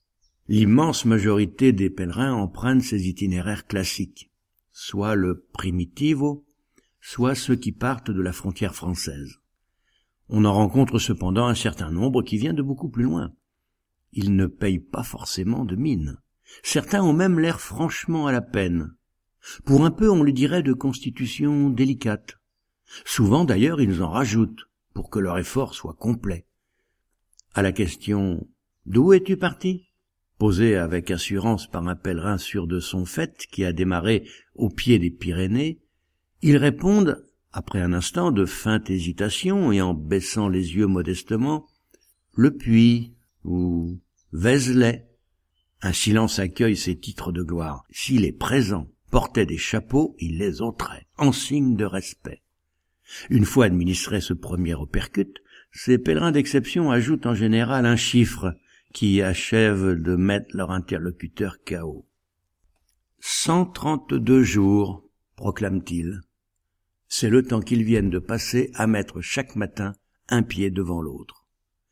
voix naturelle, nerveuse, peut être jouée, idéale pour livres audios et voice over
Sprechprobe: Sonstiges (Muttersprache):